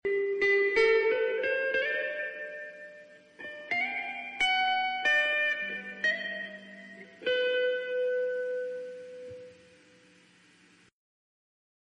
the chord is just C D G but here's the tabs